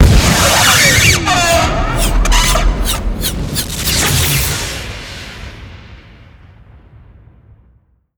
Add ghost sounds.
avo_death.LN65.pc.snd.wav